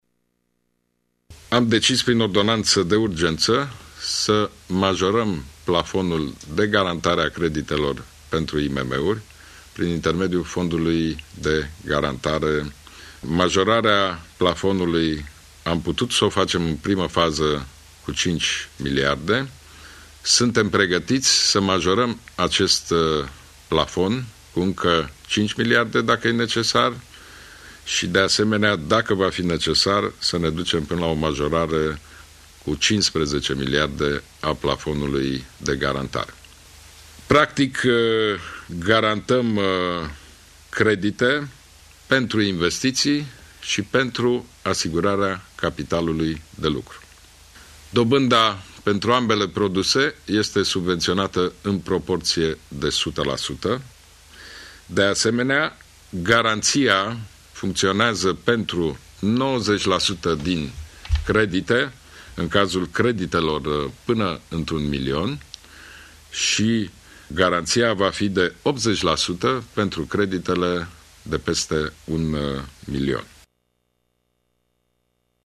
Guvernul a decis să majoreze plafonul de garantare a creditelor pentru IMM-uri cu 5 miliarde de lei, a anunţat, în urmă cu puțin timp, premierul Ludovic Orban.